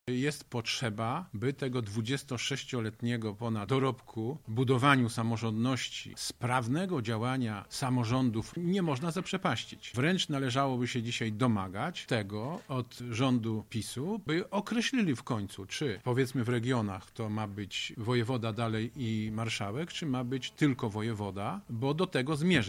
Sławomir Sosnowski, marszałek województwa lubelskiego, wypowiedział się na ten temat